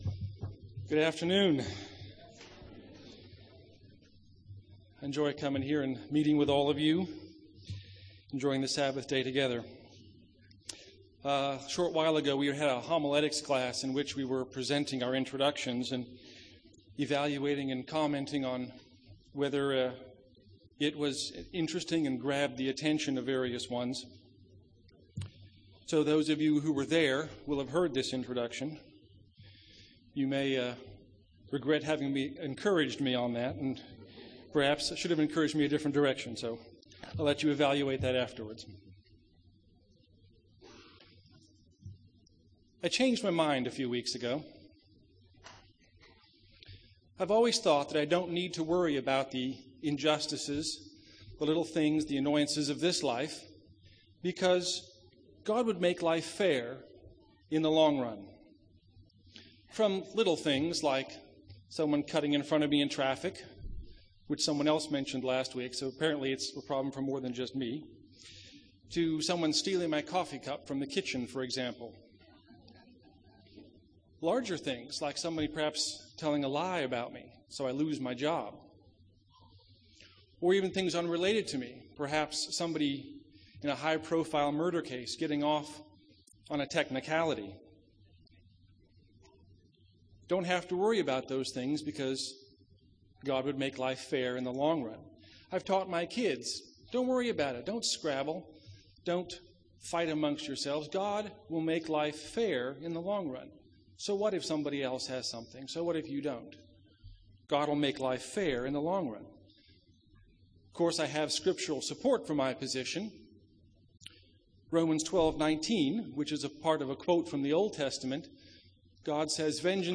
Do we believe that it's God's role to make life fair in the long run? This sermon discusses God's role and our response to offense.
Given in Dallas, TX